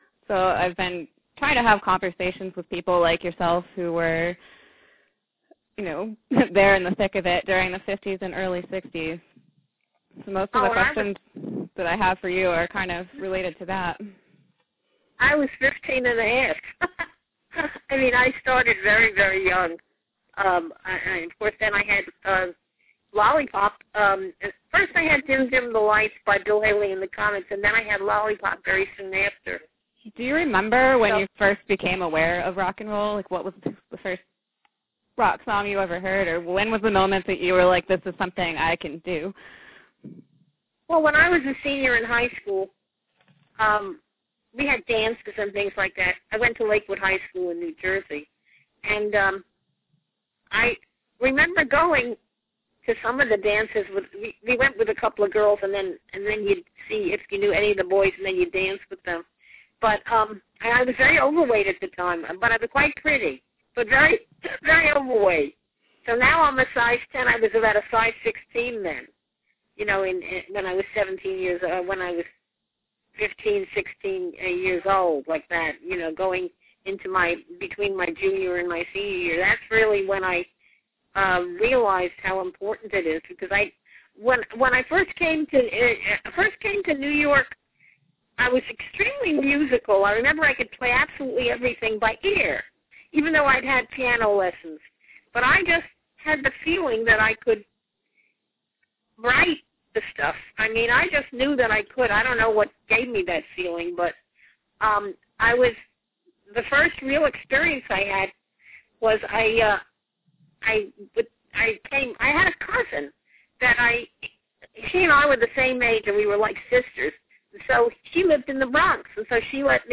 Excerpts from a phone interview
Lightly edited due to poor audio quality.